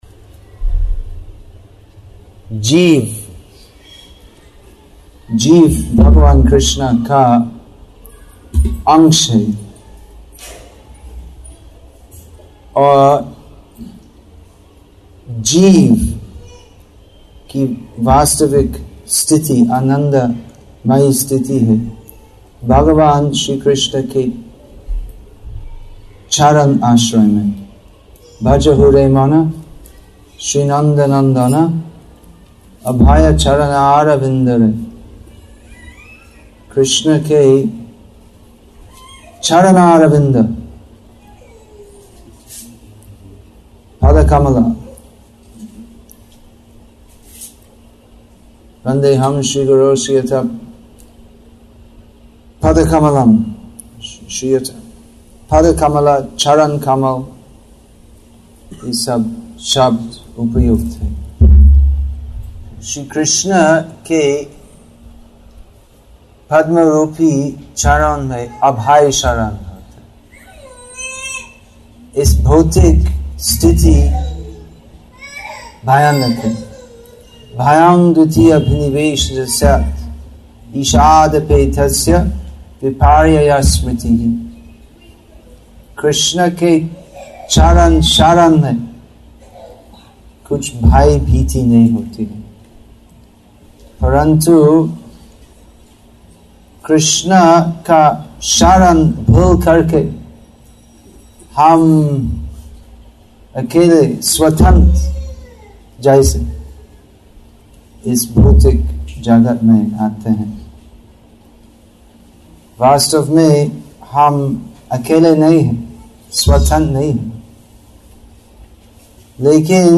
Assorted Lectures